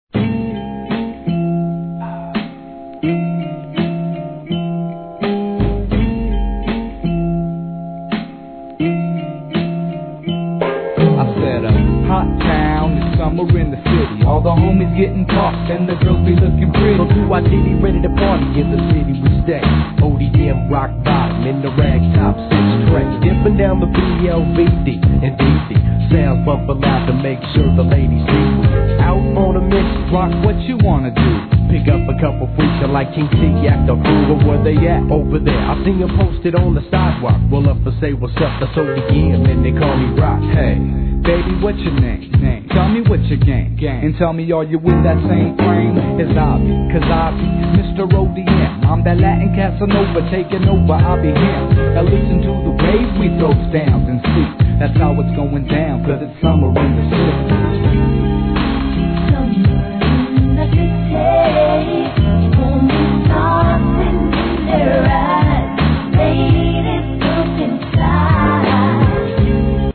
G-RAP/WEST COAST/SOUTH
使いのレイドバックにフィメール・ヴォーカルを絡めた1996年の夕暮れG-CLASSIC!!